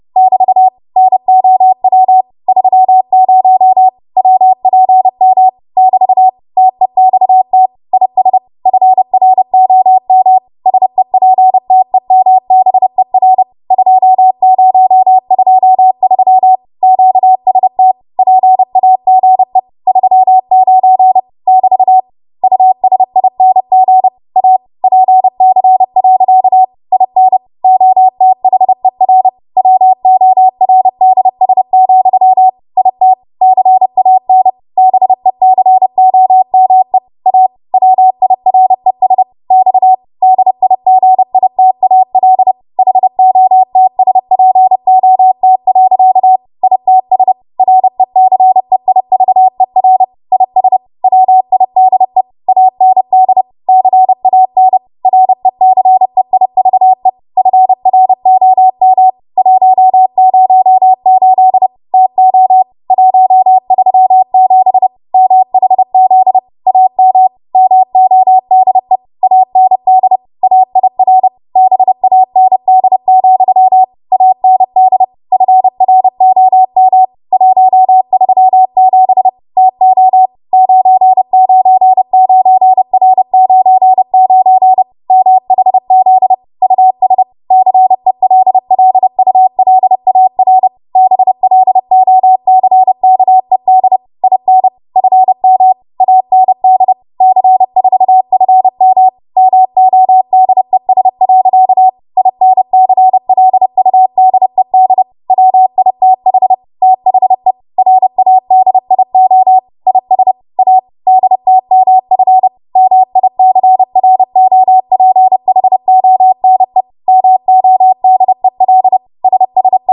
30 WPM Code Practice Archive Files
Listed here are archived 30 WPM W1AW code practice transmissions for the dates and speeds indicated.
You will hear these characters as regular Morse code prosigns or abbreviations.